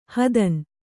♪ hadan